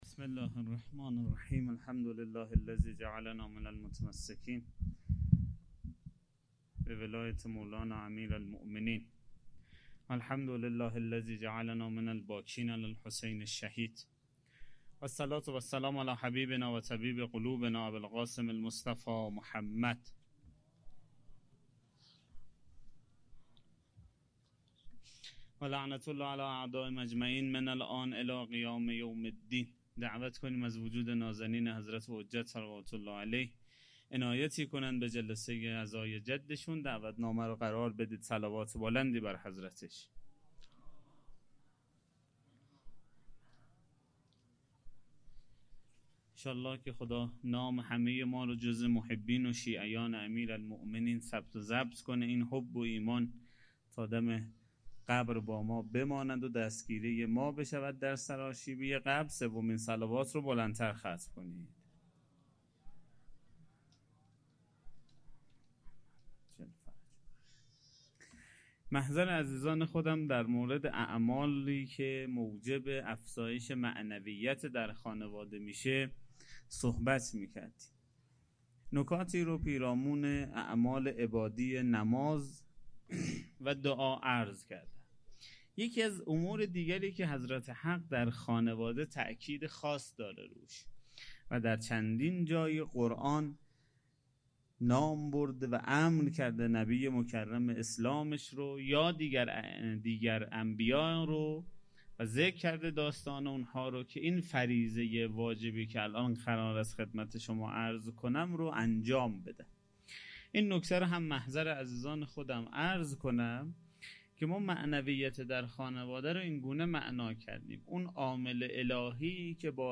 سخنرانی شب هفتم